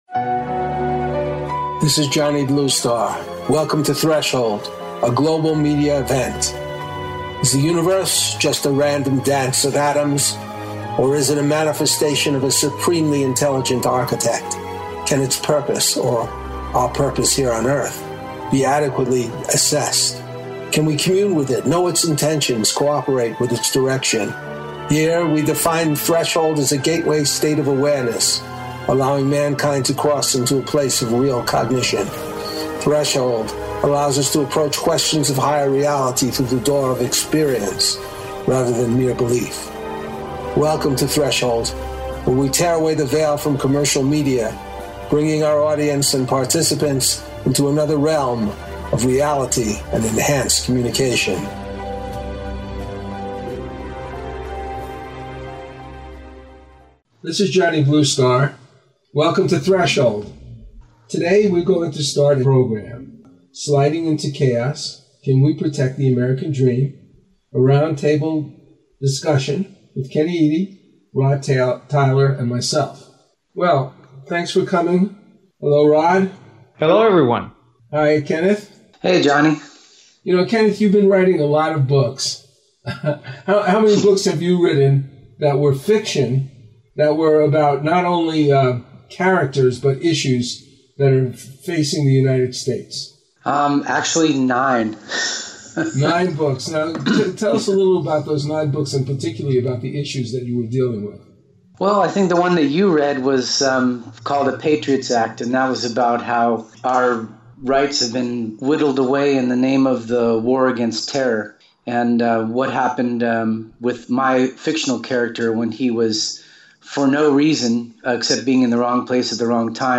In this show, the round table discusses the erosion of civil liberties in the United States, including the attacks on privacy, which has been extended to the current current administration. How can we protect the American dream when many governmental and commercial policies are detrimental to the economic and political reality of the United States?